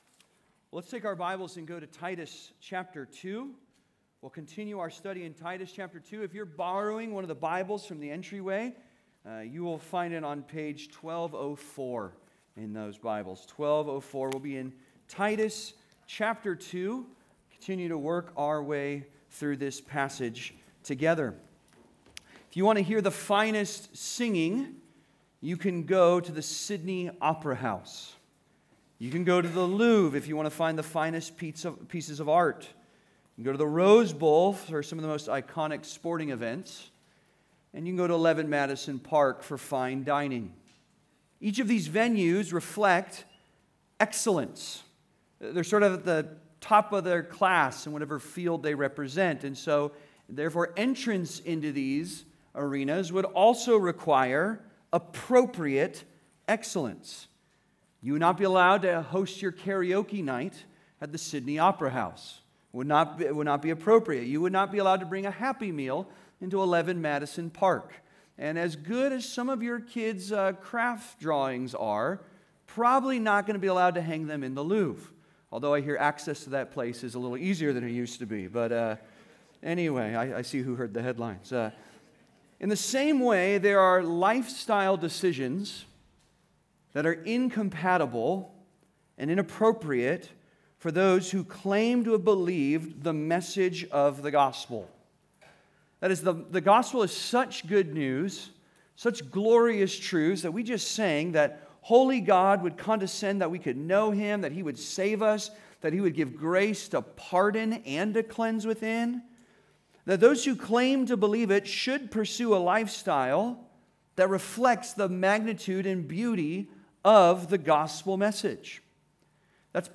Living our Doctrine Part 1 (Sermon) - Compass Bible Church Long Beach